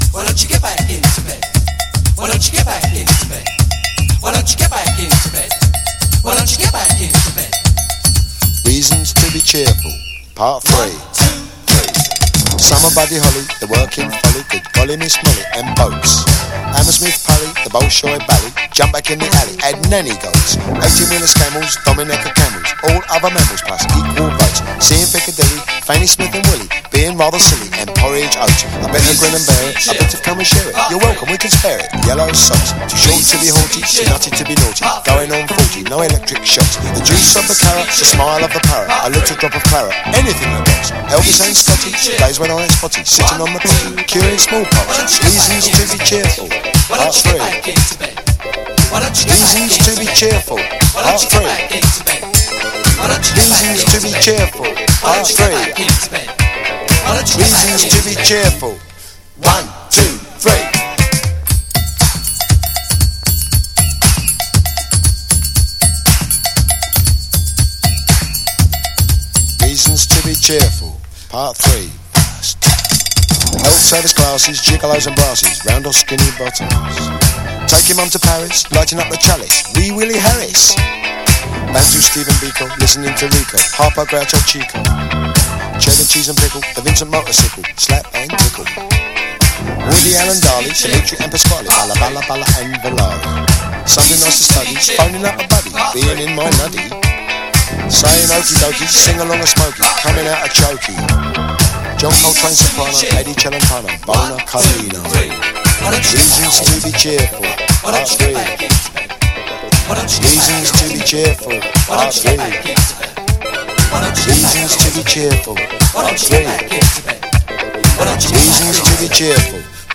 NEW WAVE / ELE POP / DISCO / SYNTH POP
80'Sシンセ・ポップ/エレクトリック・ディスコな名曲を収録したUS編集盤！
甘いメロディに歯切れ良いビートでフロアのハートを打ち抜く名曲ですが